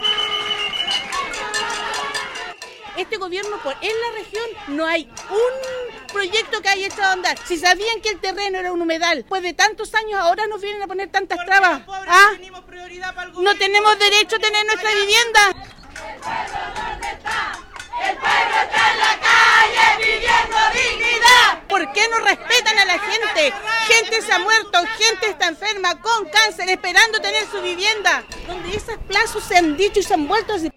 Al llegar al lugar, salió al exterior de las dependencias el seremi de Vivienda, Daniel Barrientos, a quien los manifestantes interpelaron en reiteradas ocasiones, cuestionando el tiempo de espera y la serie de retrasos que han tenido que enfrentar para la obtención de una solución habitacional .